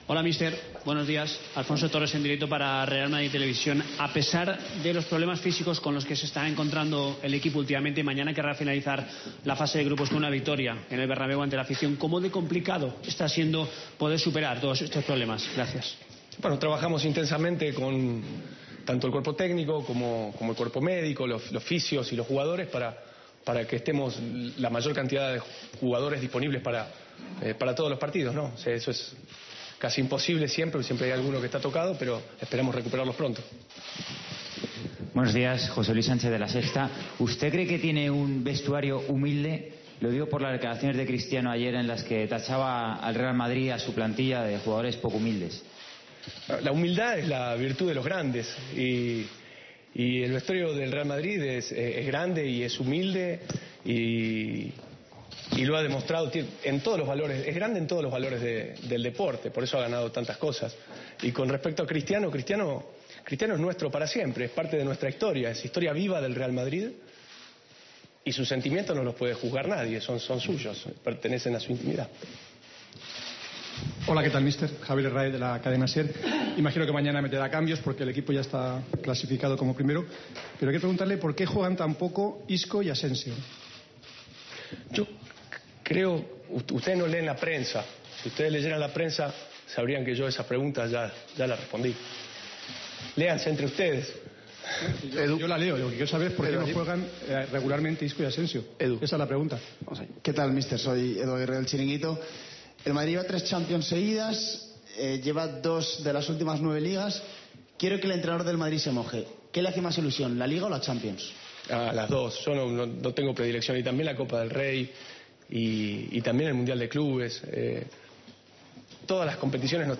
Así lo afirmó Solari en rueda de prensa, que no desea como ha ocurrido recientemente regresos fugaces del lateral zurdo brasileño que ha enlazado varios percances musculares.